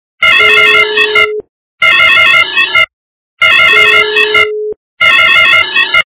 При прослушивании Автосигнализация - Хаотический сигнал качество понижено и присутствуют гудки.
Звук Автосигнализация - Хаотический сигнал